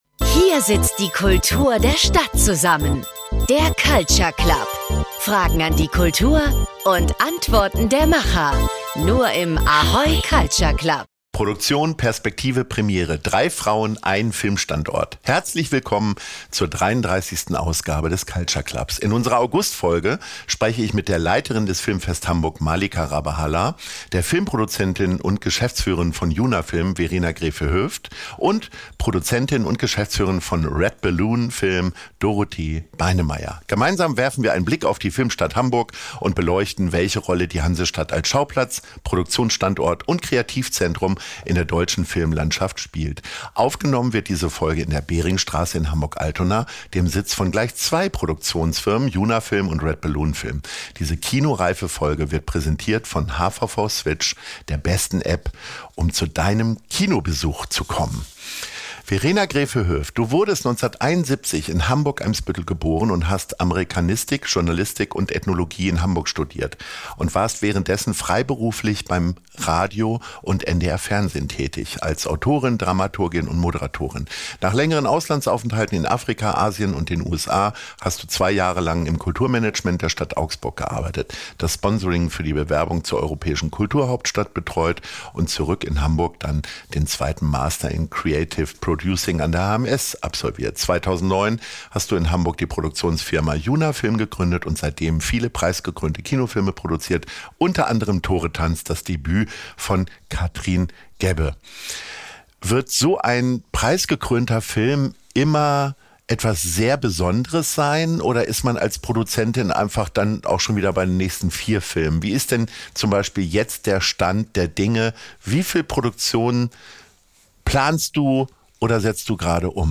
Aufgenommen wurde die Folge in der Behringstraße in Hamburg-Altona, dort, wo mit Junafilm und Red Balloon Film gleich zwei Produktionsfirmen zuhause sind.